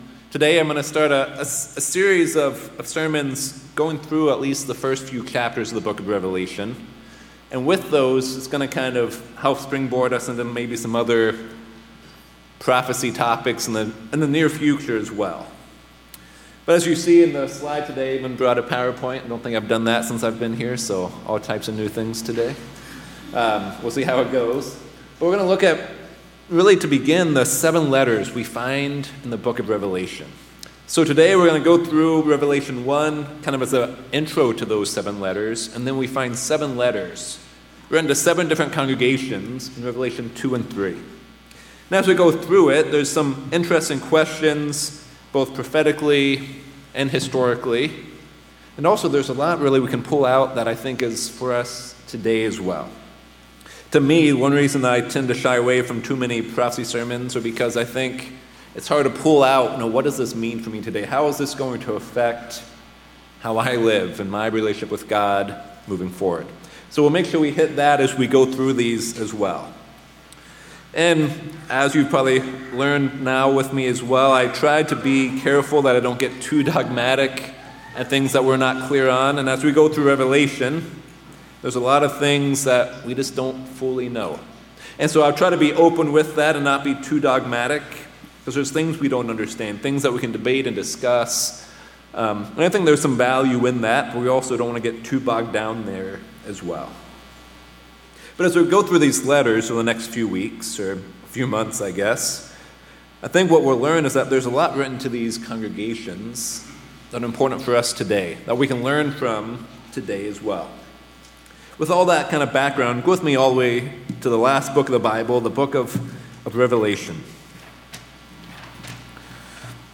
In the book of Revelation, we see letters written to the seven churches located in Asian Minor or modern day Turkey. In this new sermon series, we are going to begin by examining the letter written to the church of Ephesus, the church that has lost its first love